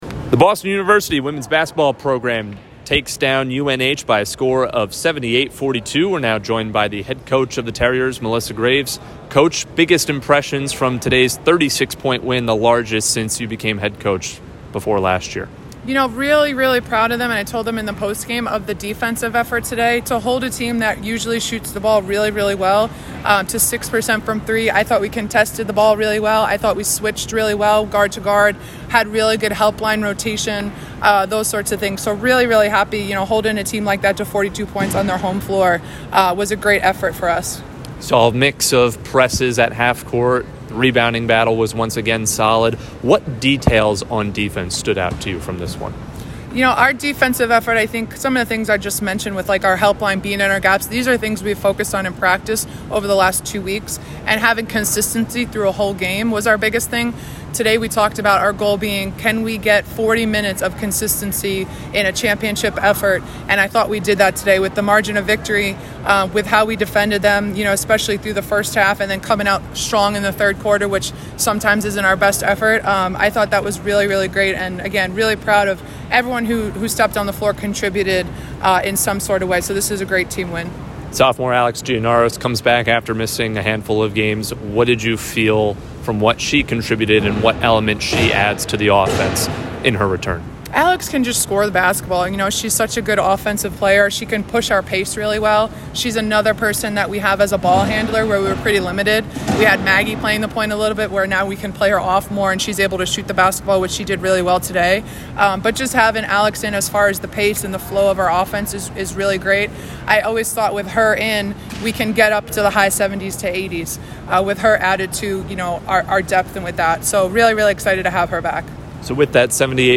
WBB_UNH_Postgame.mp3